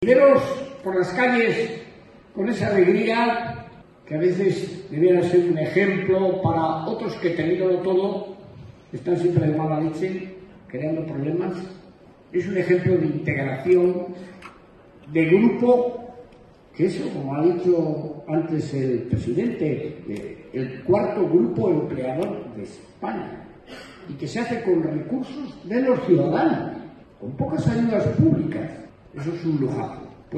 Foto de familia de los premiados junto a las autoridadesLa semana siguiente, el jueves 24 de octubre, fue la ONCE de Cantabria la que rindió homenaje al espíritu solidario de la sociedad montañesa con la entrega de sus galardones autonómicos 2019, en una gala celebrada en un abarrotado Teatro Casyc de Santander y presidida por los máximos responsables de la Comunidad Autónoma, Miguel Ángel Revilla, y del Grupo Social ONCE, Miguel Carballeda.